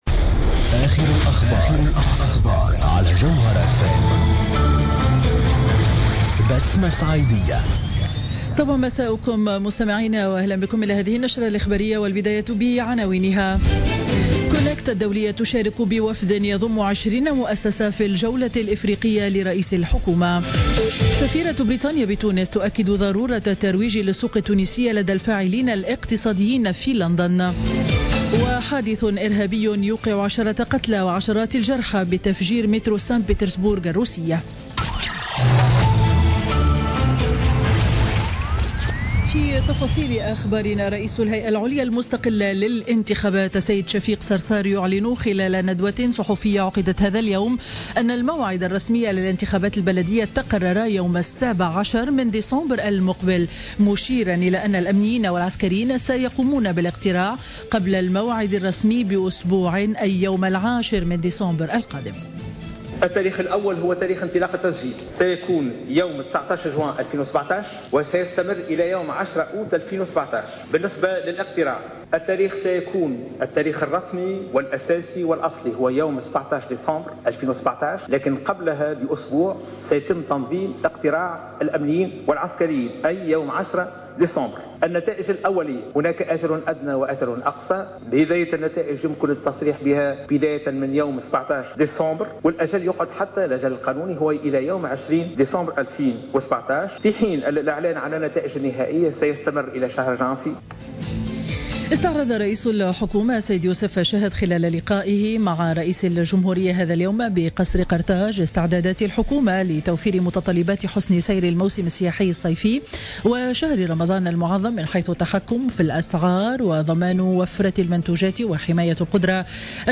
نشرة أخبار السابعة مساء ليوم الاثنين 3 أفريل 2017